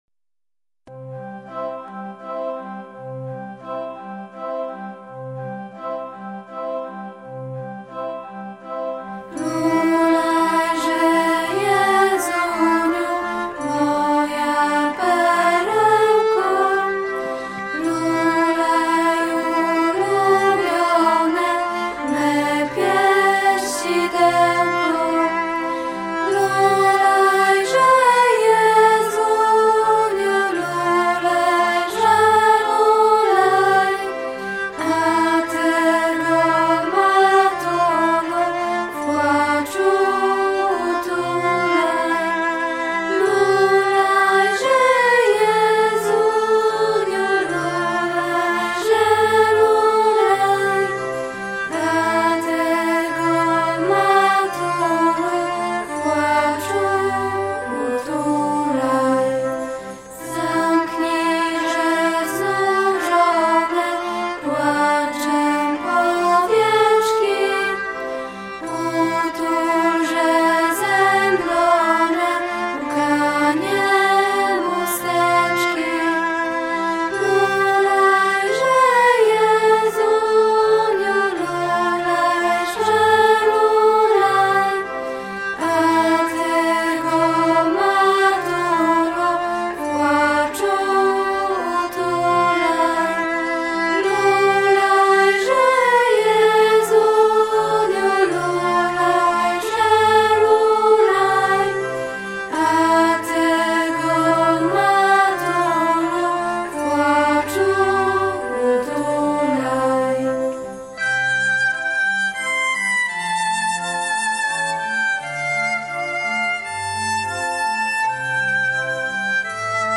Kolędy:
Podsumowanie przedstawienia jasełkowego, które uczniowie naszego gimnazjum przygotowali z okazji Świąt Bożego Narodzenia 2010.